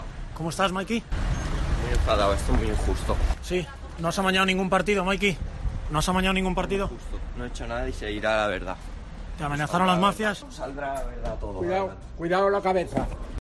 a la entrada de los juzgados de Elda